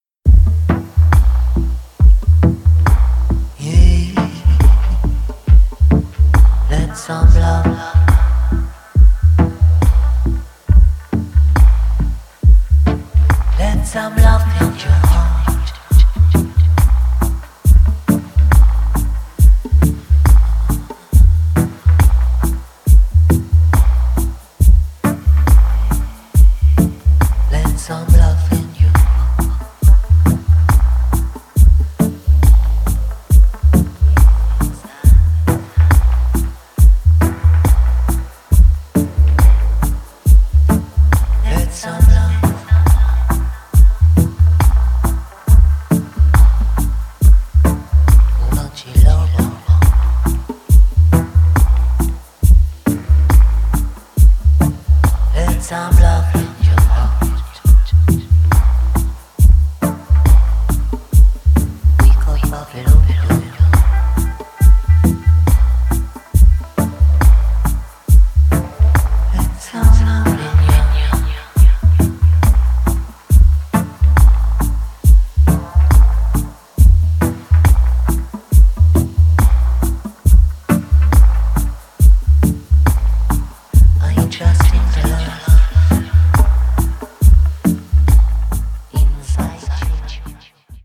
無駄無く削ぎ落とされたモダンでミニマルな音響から浮かび上がるアーシーな郷愁。